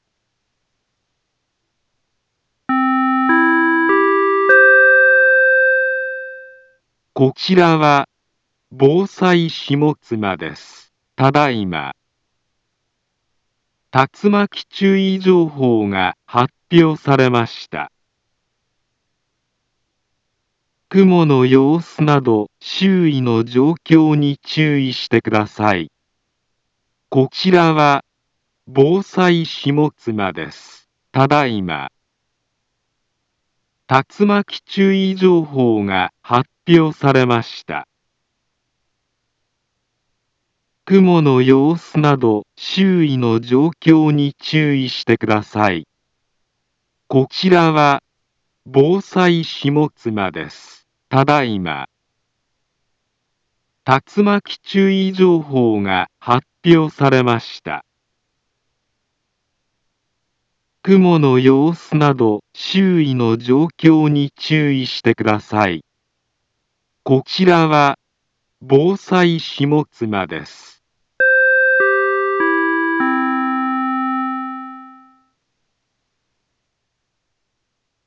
Back Home Ｊアラート情報 音声放送 再生 災害情報 カテゴリ：J-ALERT 登録日時：2025-08-18 17:18:26 インフォメーション：茨城県南部は、竜巻などの激しい突風が発生しやすい気象状況になっています。